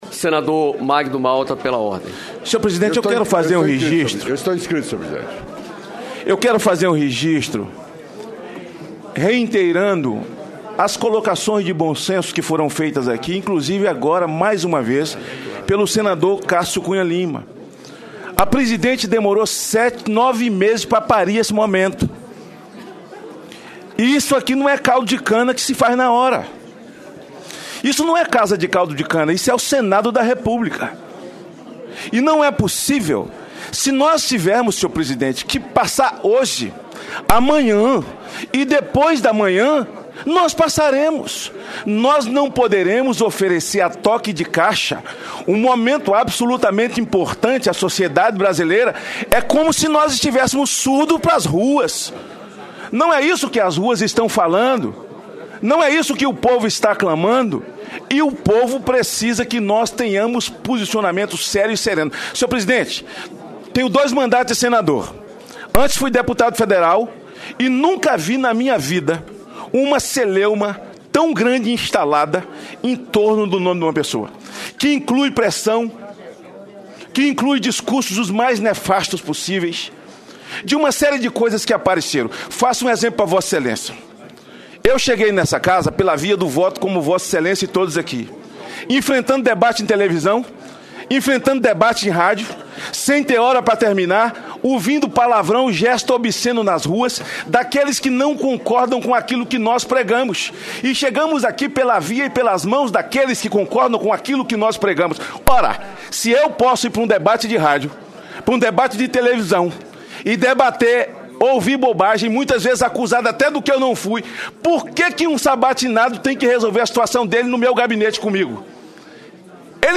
Confira a íntegra dos principais debates da Comissão de Constituição, Justiça e Cidadania do Senado